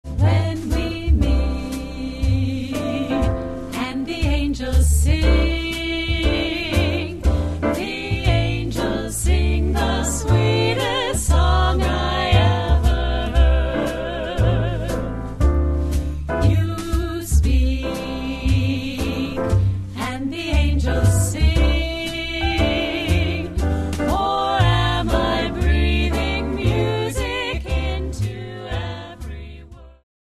Piano
Drums
Bass
Trumpet
Trombone
Alto Sax
Tenor Sax
studio jazz band arrangements